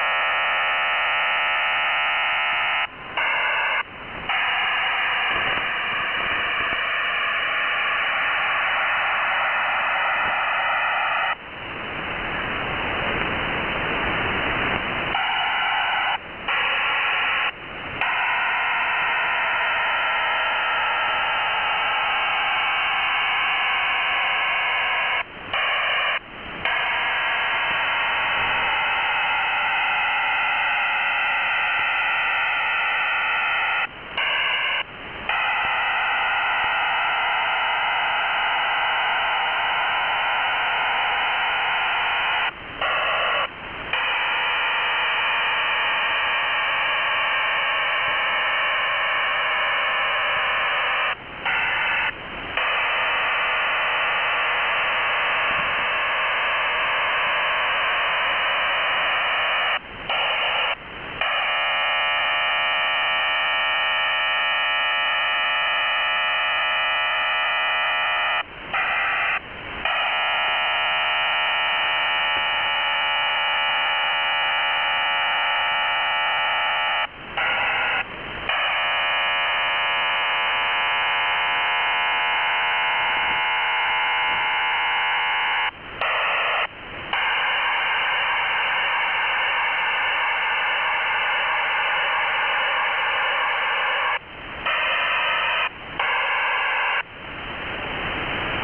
Начало » Записи » Радиоcигналы на опознание и анализ
Неизвестный сигнал